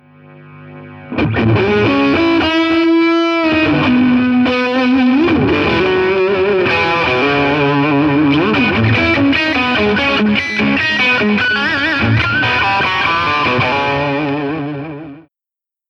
electric guitar.
mod12top5_content_electricguitar.mp3